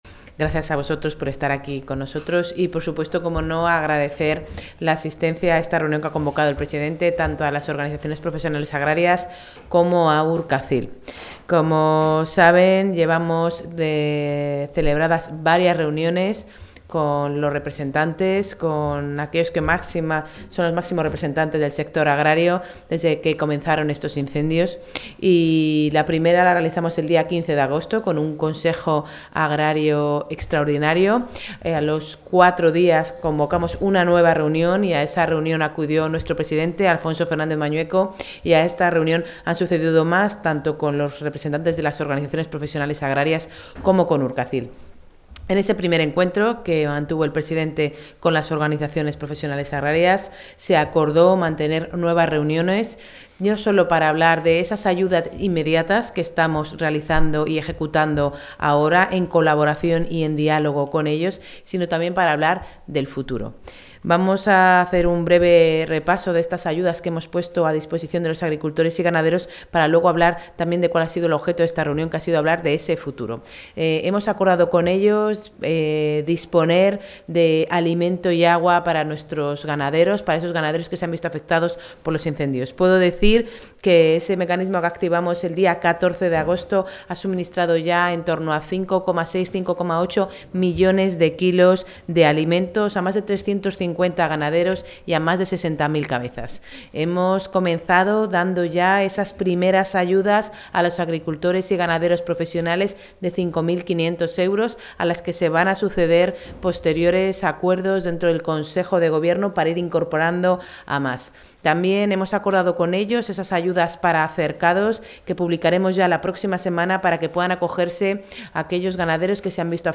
Intervención de la consejera de Agricultura.